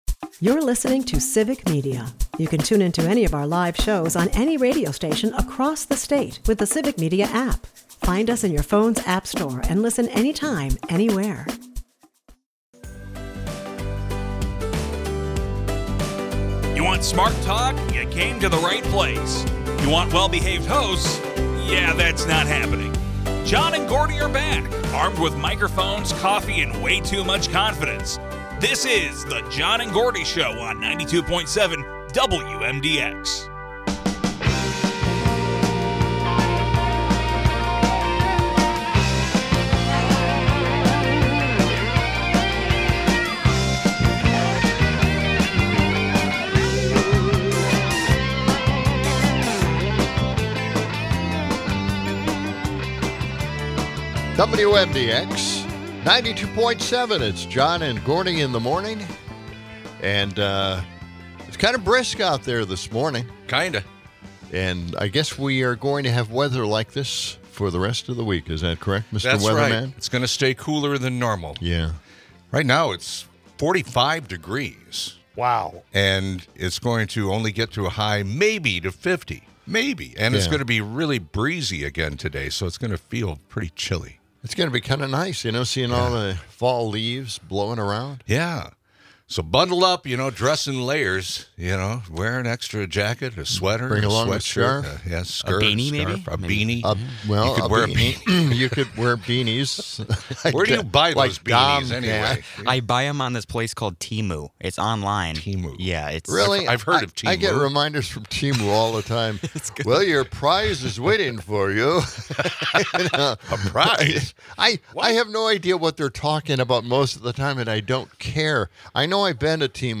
The duo debates the ethics of Turning Point USA's presence in schools, likening it to Antifa after-school programs. Meanwhile, climate concerns escalate with CO2 levels skyrocketing, yet the EPA pushes massive deregulation.